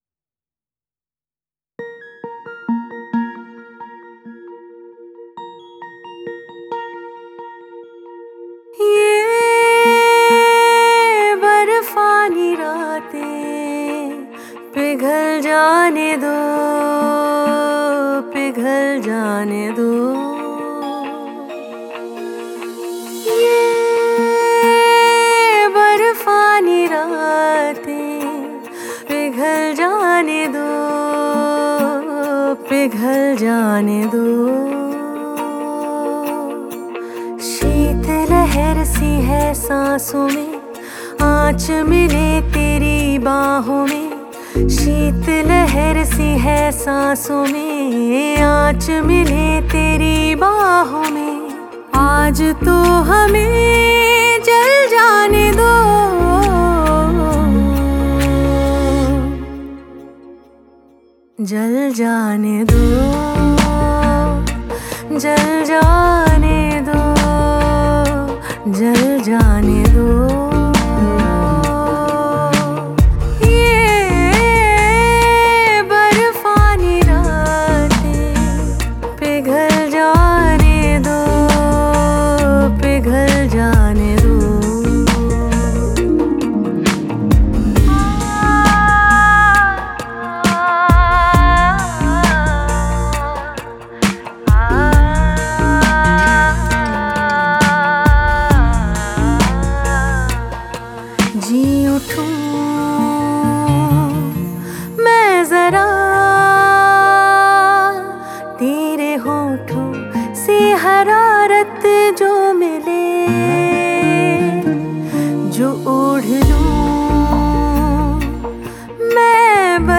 Bollywood Mp3 Music 2017